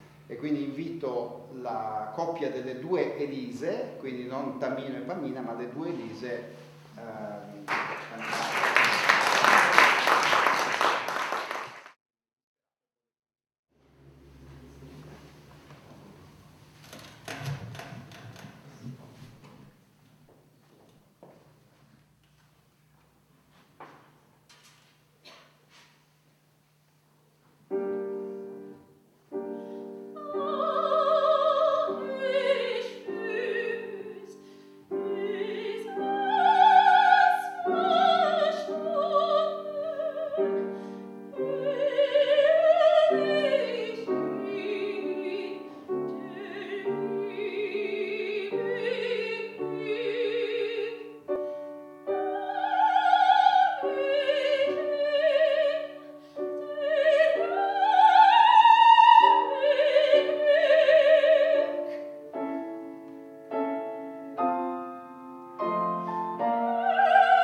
soprano
pianoforte